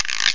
drum_kits
.13Scraper_.mp3